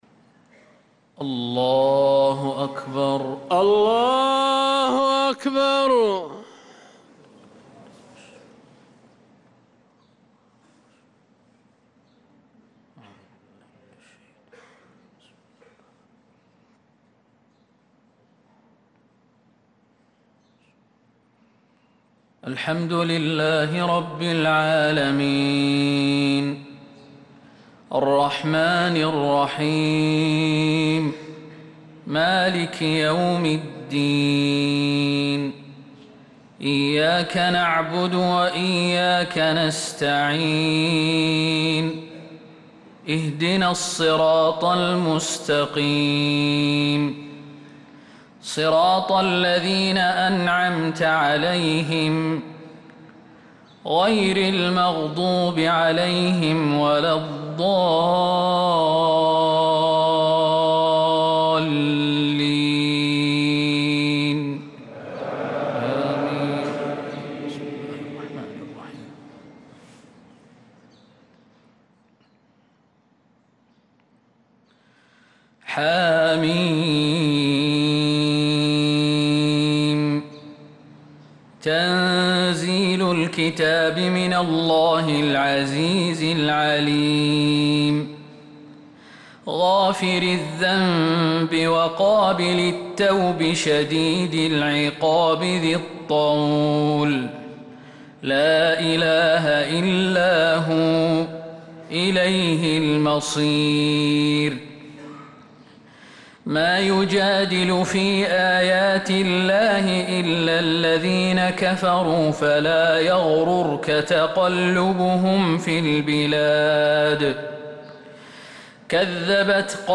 صلاة الفجر للقارئ خالد المهنا 19 ربيع الأول 1446 هـ
تِلَاوَات الْحَرَمَيْن .